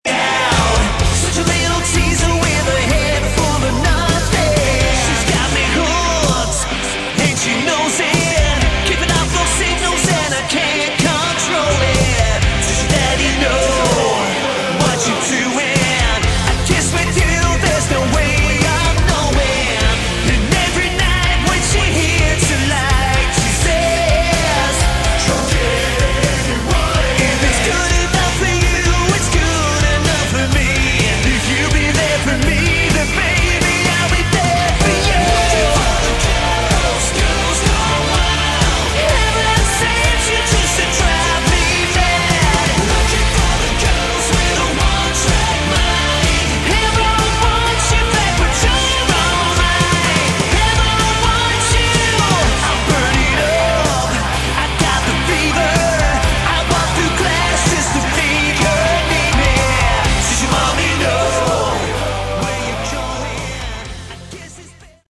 Category: Hard Rock
vocals
guitar
keyboards
bass
drums